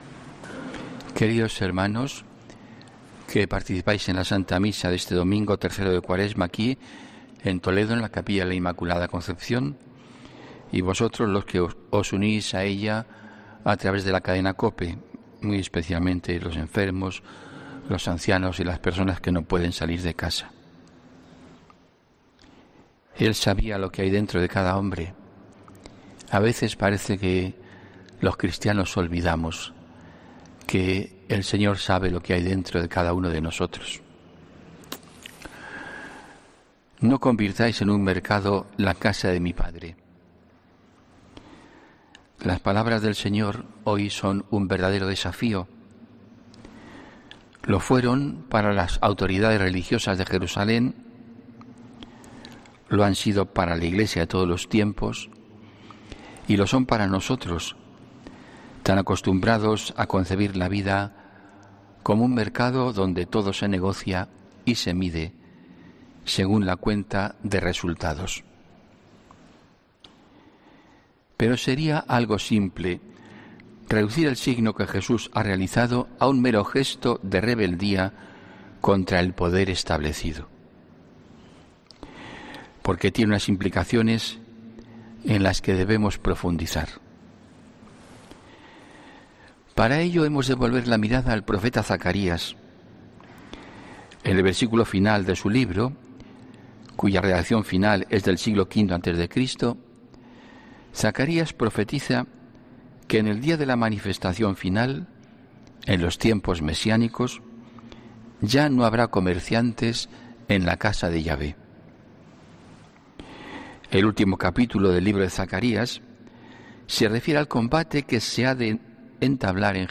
HOMILÍA 7 MARZO 2021